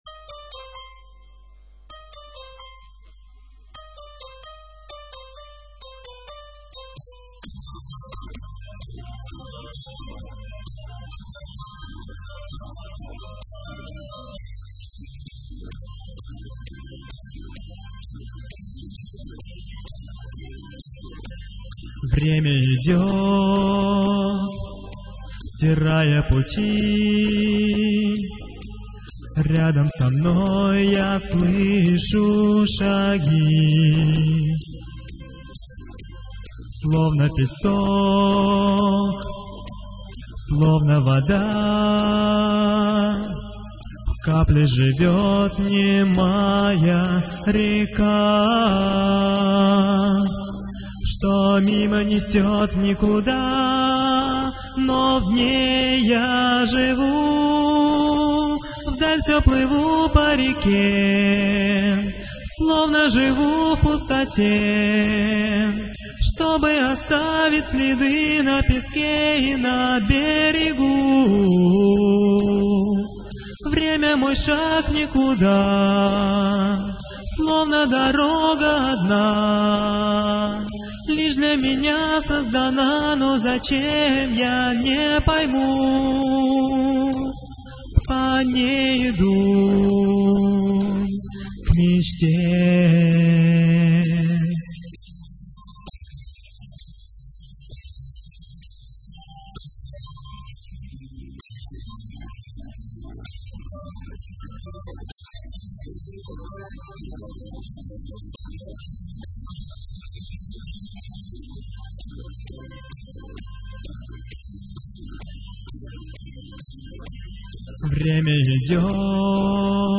Прошу прощения за качество mp3 - они сильно сжаты для уменьшения объема.